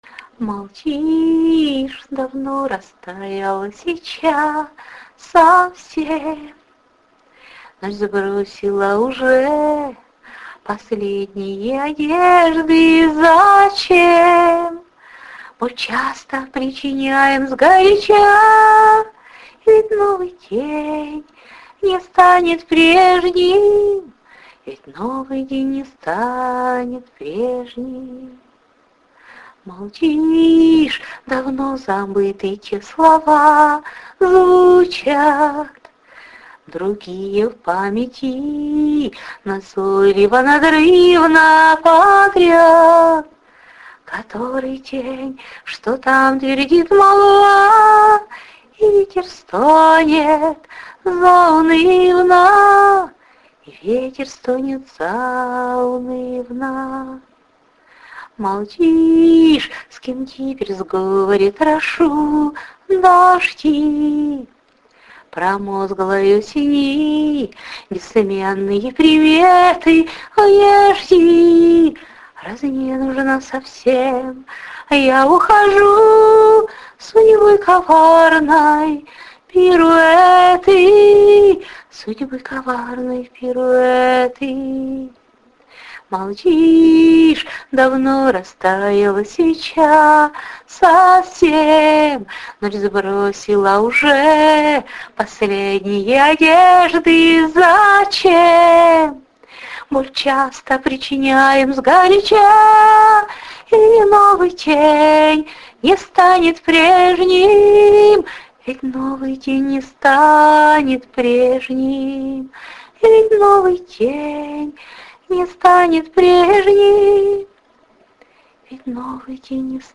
Спасибо,жаль,не владею музыкальными инструментами. 39 39 39 cry 16 16
12 Не каждый сможет сочинить мелодию к словам,и спеть не каждому дано красиво!